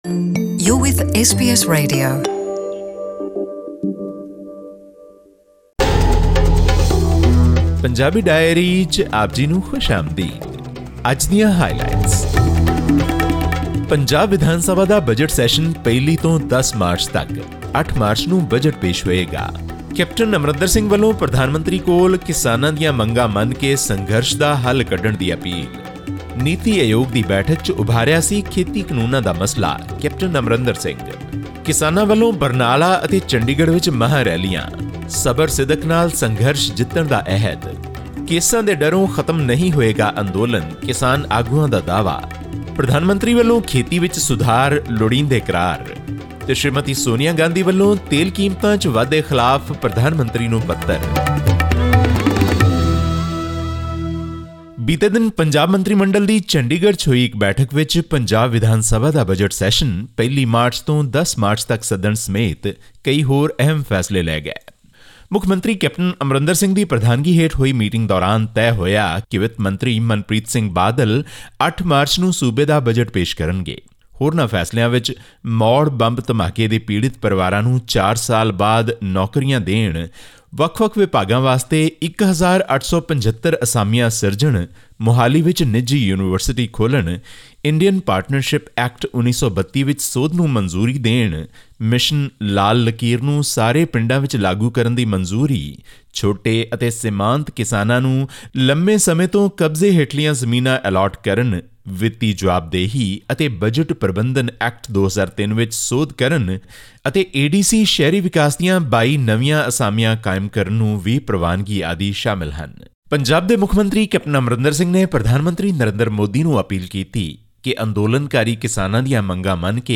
In yet another mass mobilisation after the January 26 tractor parade in New Delhi, thousands of farmers gathered in Barnala on 21 February. The rally has been billed as an attempt by farmer unions to highlight the 'non-religious character' of the anti-farm law protests. Tune into our weekly news wrap from Punjab for details.